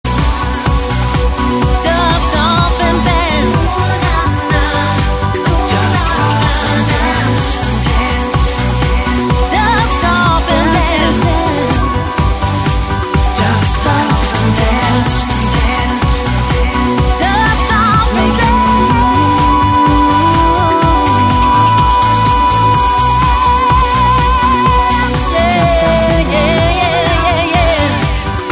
fusing elements of pop, rock, alternative & euro
Euro-laced